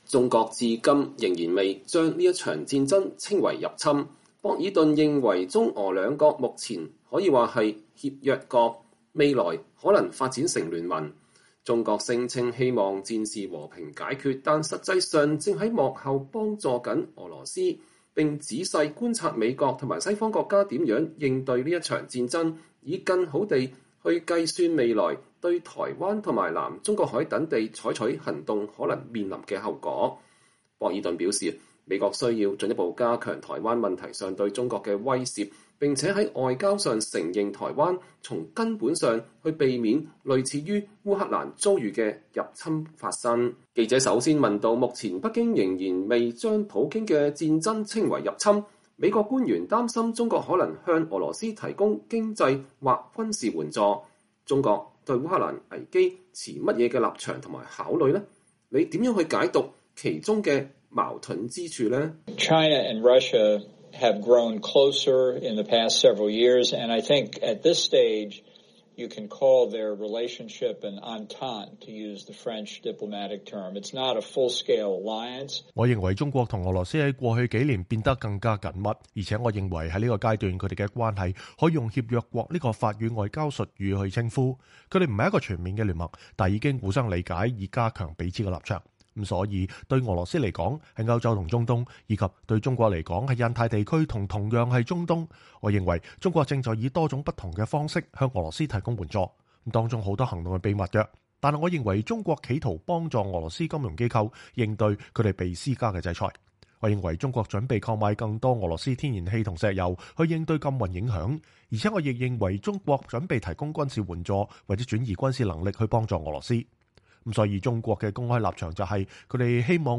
專訪美國前國安顧問博爾頓：從烏克蘭局勢看美中台關係